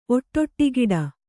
♪ oṭṭoṭṭigiḍa